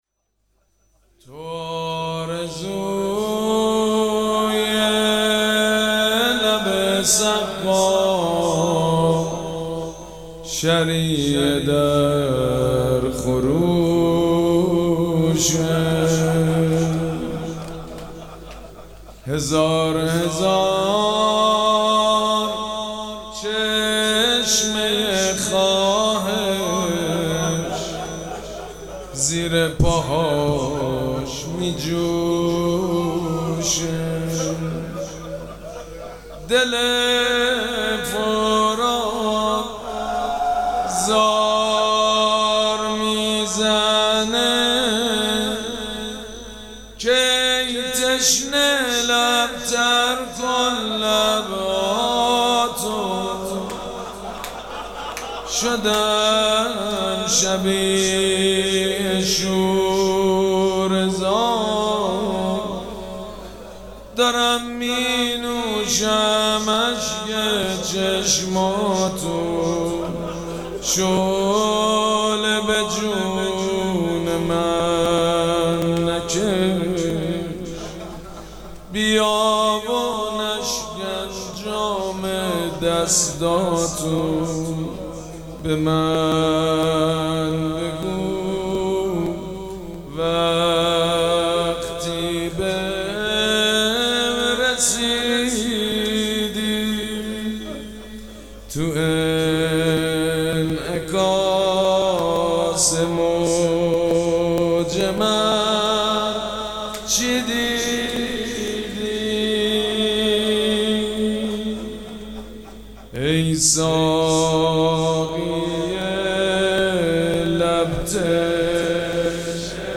مراسم عزاداری شب نهم محرم الحرام ۱۴۴۷
روضه
حاج سید مجید بنی فاطمه